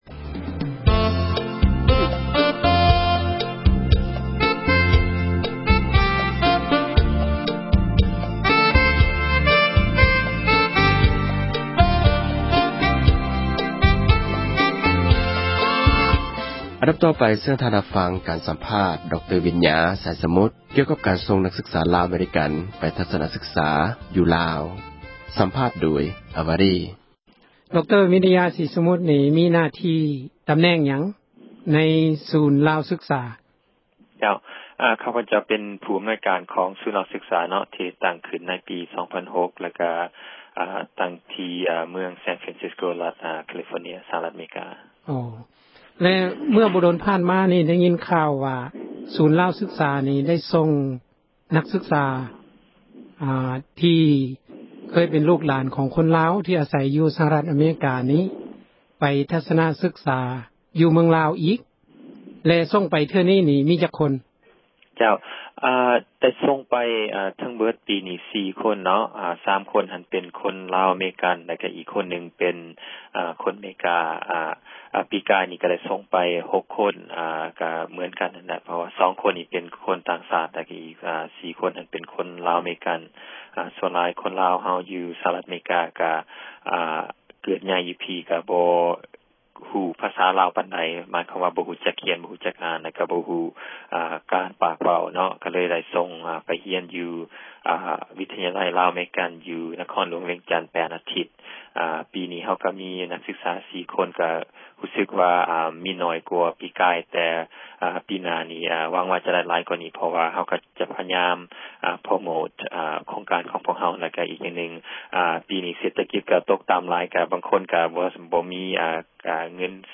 ການສໍາພາດ